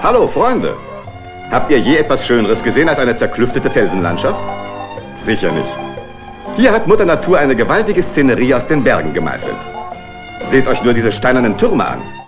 Die Samples haben jetzt auch besere Qualität.